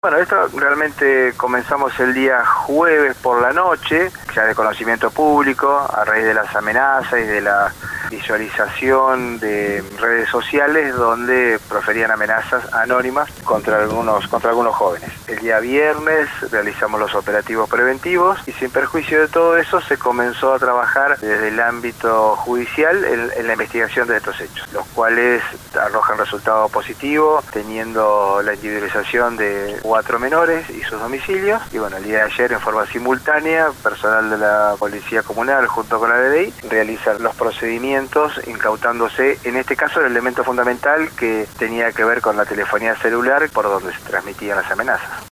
El Secretario de Seguridad municipal, Juan Apolonio hizo referencia en contacto con LU 24 a las acciones llevadas adelante en los allanamientos por las amenazas a establecimientos escolares durante la jornada del martes.